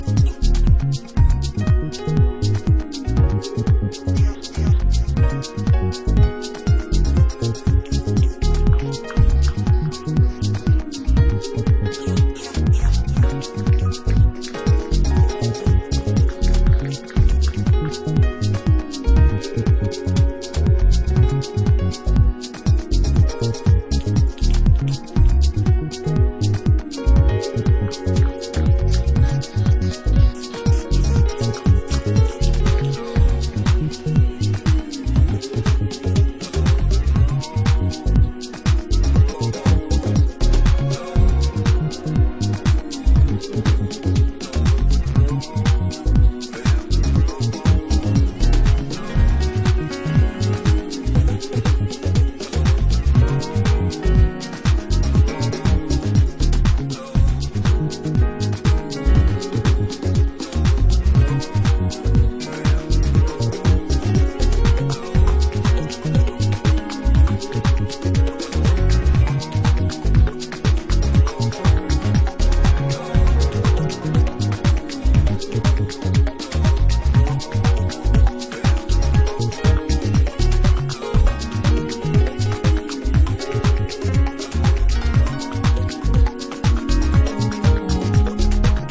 spacey chords, rich basslines, and Terrace-flavored beats